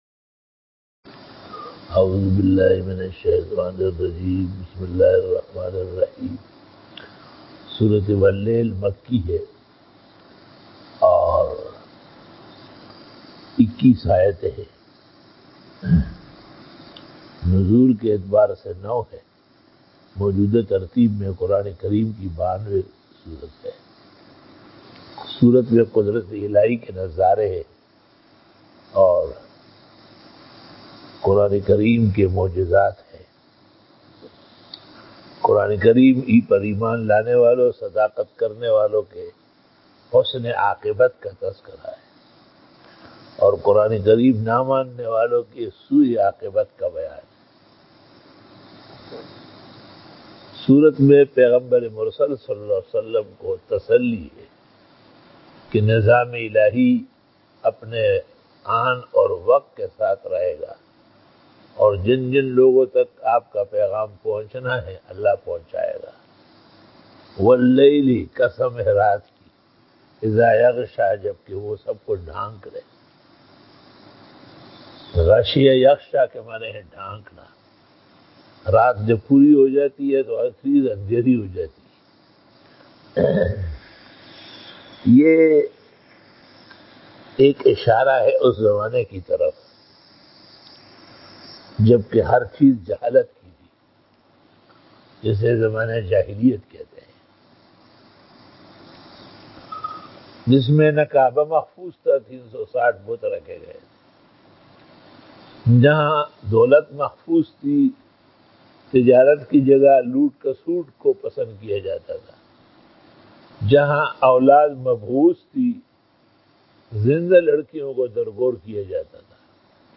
Bayan by